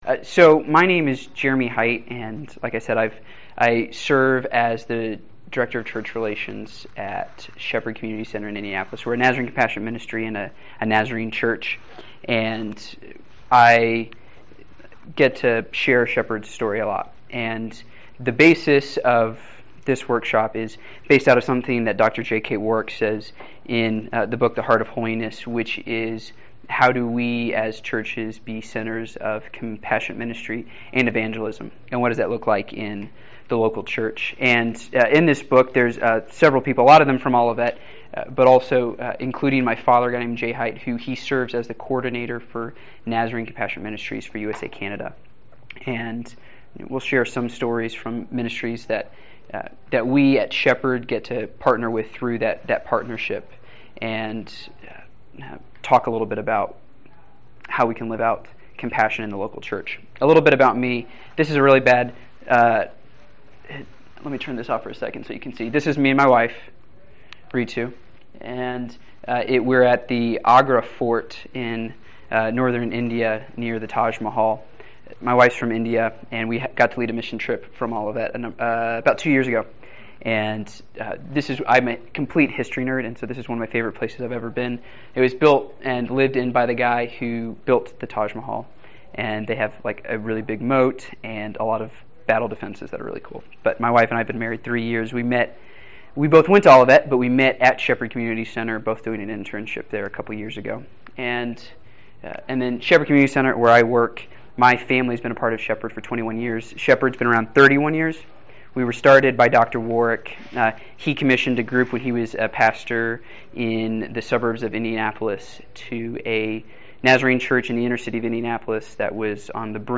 It has been said that every local church must be a center of compassionate ministry. This workshop focuses on how every church congregation can engage their community and the importance of a proper theology of compassionate ministry.